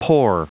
Prononciation du mot pore en anglais (fichier audio)
Prononciation du mot : pore